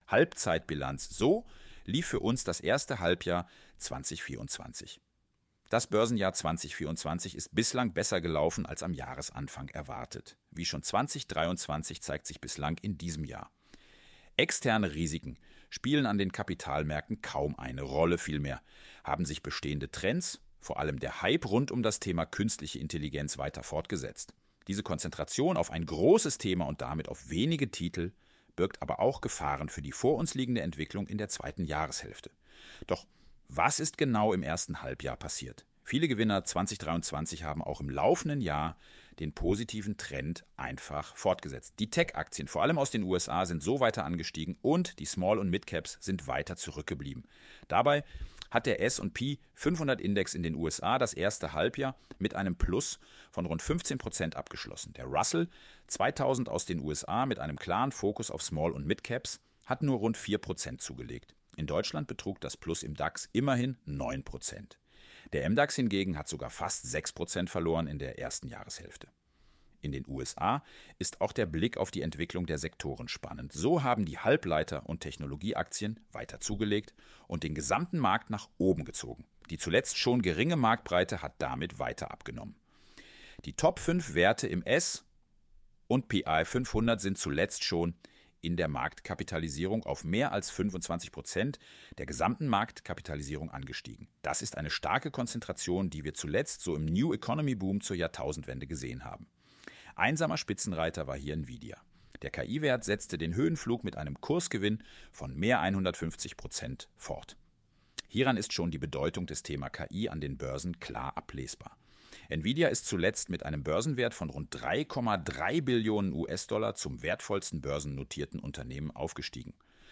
🔊 Artikel mit Audiokommentar anhören Das Börsenjahr 2024 ist bislang besser gelaufen als am Jahresanfang erwartet.